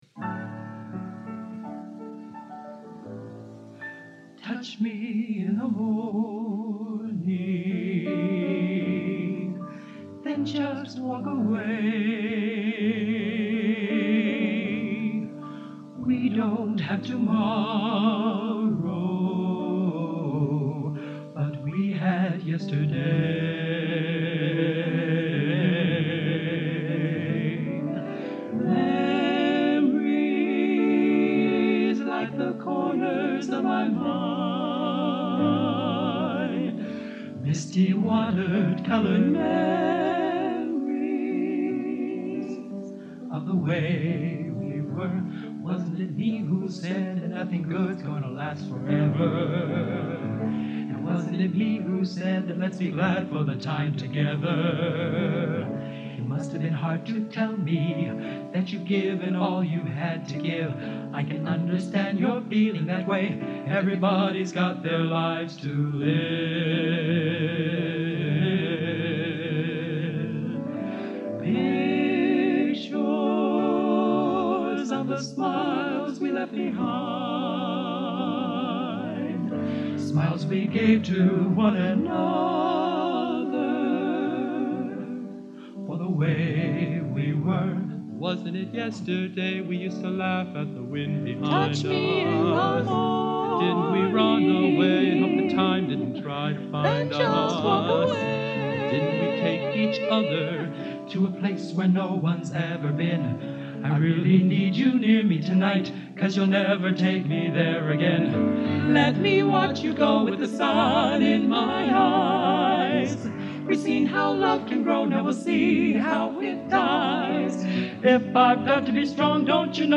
Genre: Popular / Standards Schmalz | Type: End of Season
Soft Trio